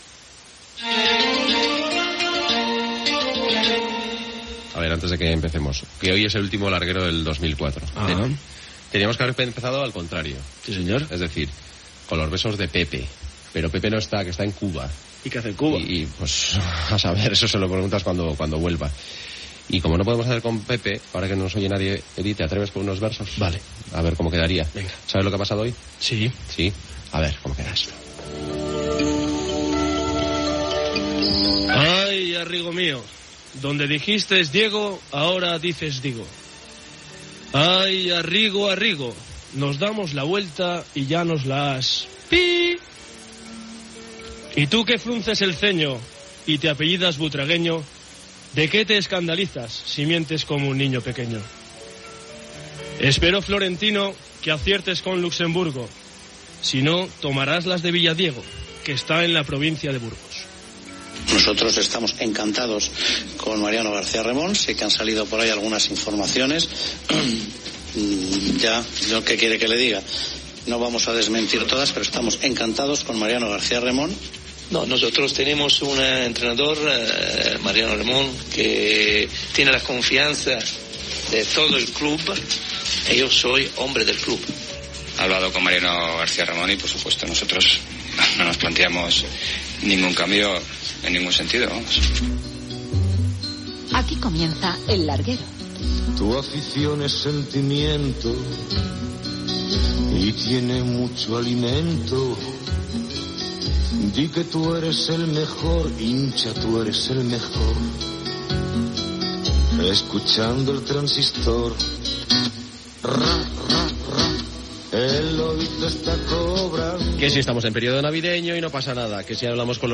e780ada103ae0e5e2a0df8e7f477f6e32264b0ec.mp3 Títol Cadena SER Emissora Ràdio Barcelona Cadena SER Titularitat Privada estatal Nom programa El larguero Descripció Comentari sobre Pepe Domingo Castaño, poema sobre l'actualitat esportiva, telèfon de participació, informació de la destitució de Mariano García Remón com entrenador del Real Madrid al que sustitueix Wanderlei Luxemburgo. Entrevista a Emilio Butragueño
Gènere radiofònic Esportiu